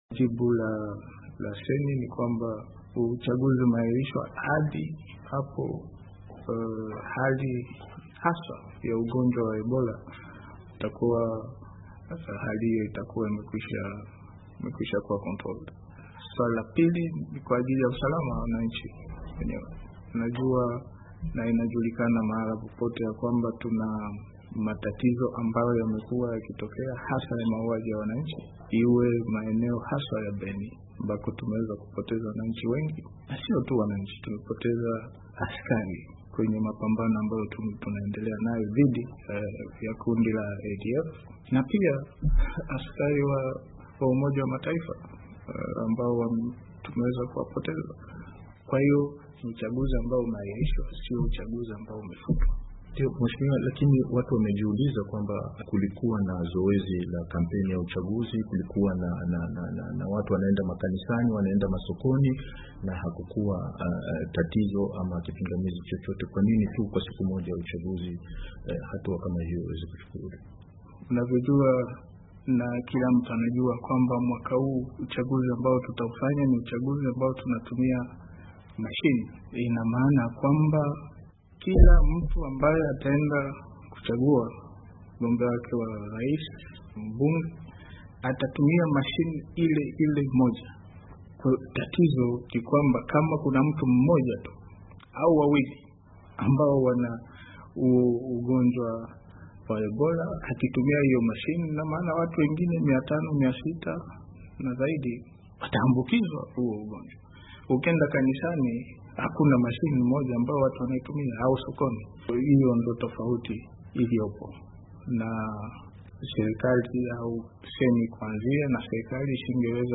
Katika mahojiano maalumu na mwandishi wa VOA mjini Kinshasa